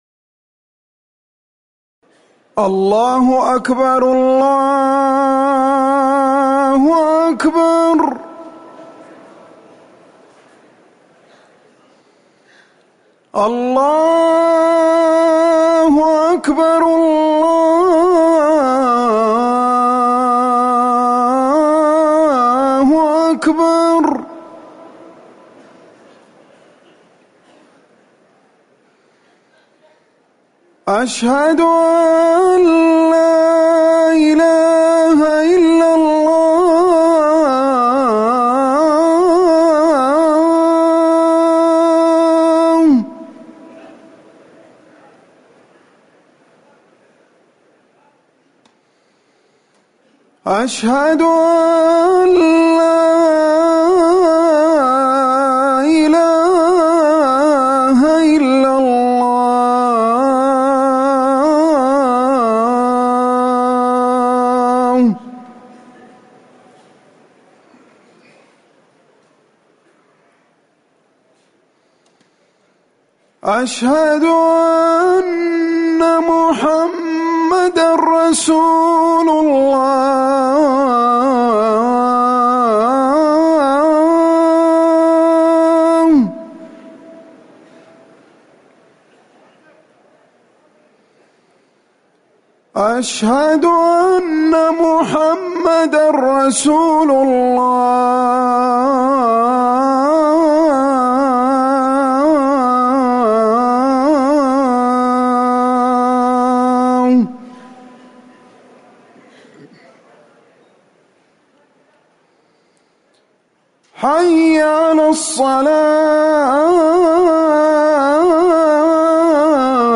أذان العشاء
المكان: المسجد النبوي